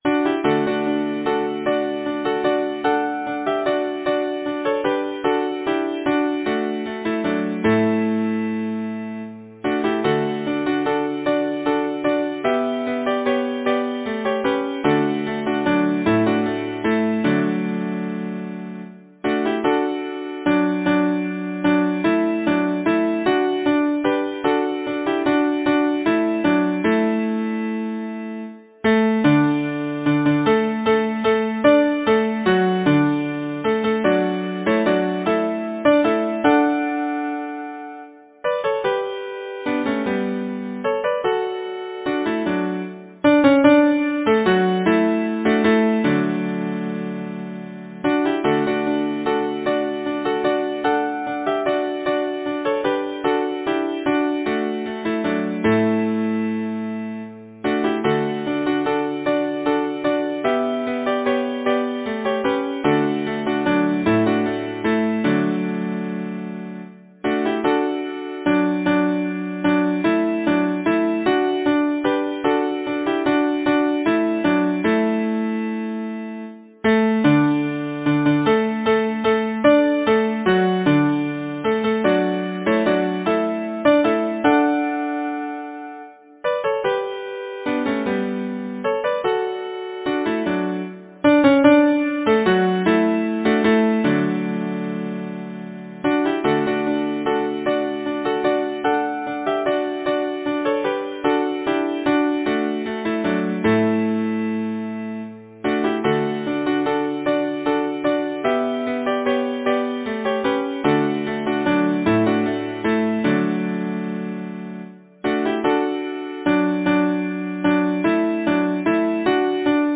Title: The March of the Cameron Men Composer: Mary M. Campbell Arranger: Henry A. Lambeth Lyricist: Mary M. Campbell Number of voices: 4vv Voicing: SATB Genre: Secular, Partsong
Language: English Instruments: A cappella